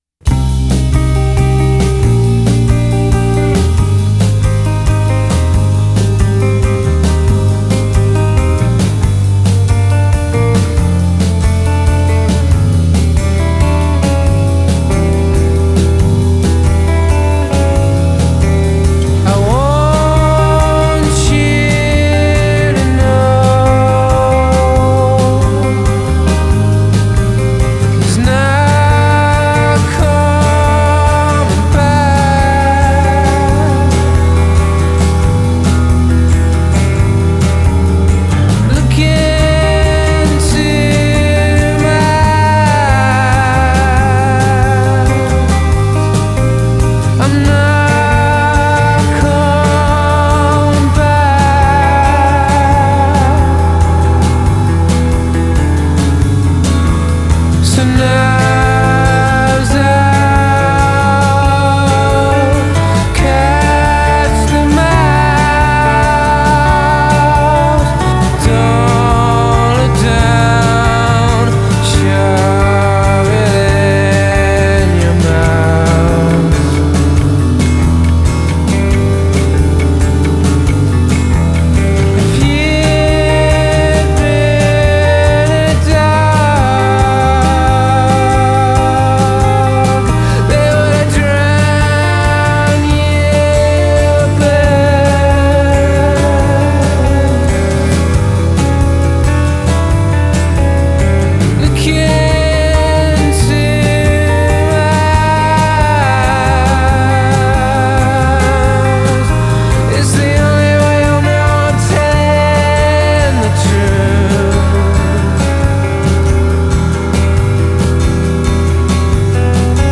از 2:26 به بعد گیتارش فوق العادست